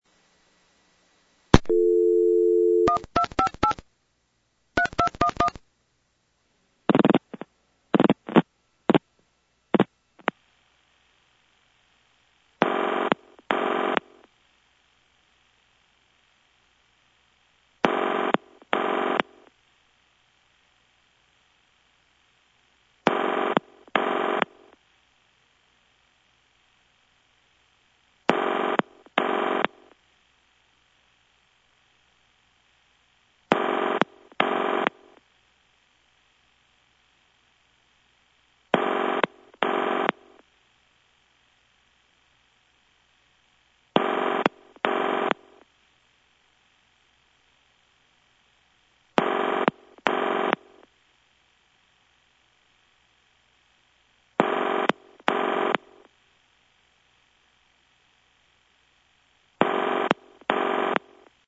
These were made over the Collector’s network  (C-Net) using an Analog Telephone Adapter (ATA) via the Internet.